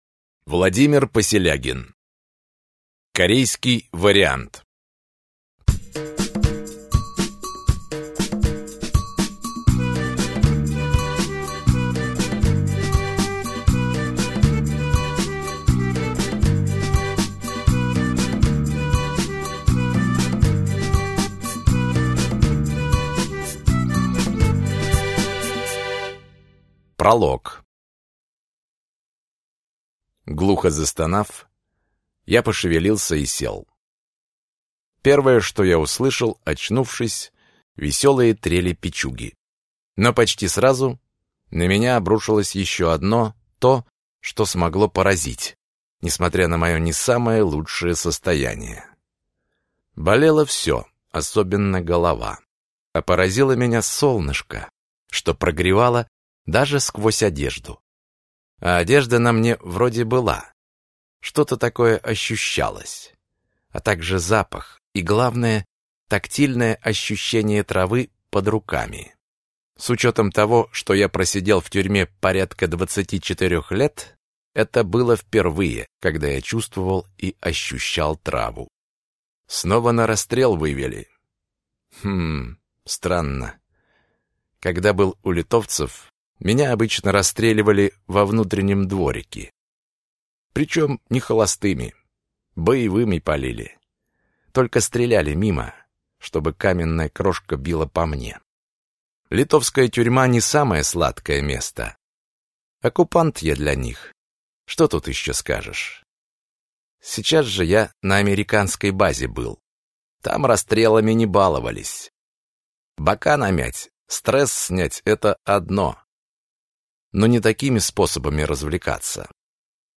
Аудиокнига Корейский вариант | Библиотека аудиокниг